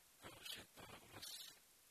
ID: 633 Longitude: -62.4145 Latitude: 55.6788 Pronunciation: ka:uʃeta:wa:kəma:s Translation: Esker Lake (small) Feature: lake Explanation: Named in reference to nearby, larger lake Kaushetauakamat (no 136).